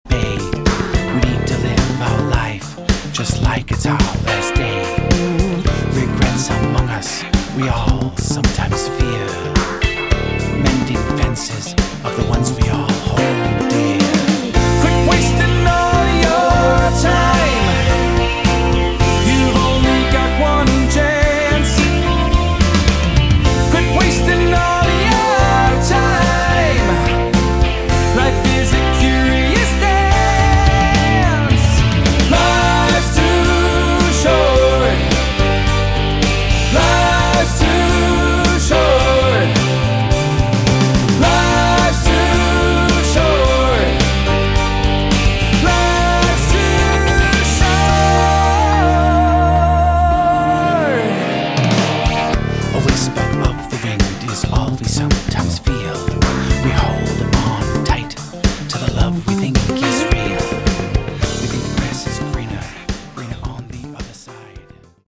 Category: AOR
lead vocals
guitars
bass guitar
drums & percussion
keyboards